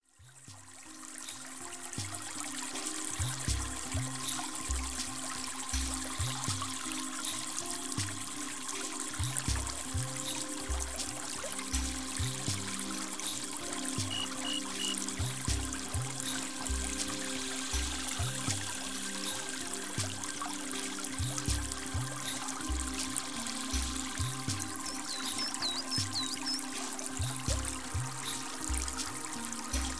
Summer fountain
Beautiful nature scenes for relaxing.
Within minutes, you find yourself in a perfect world , where your brain is stimulated and soothed by the sounds of nature.
summerfountain.mp3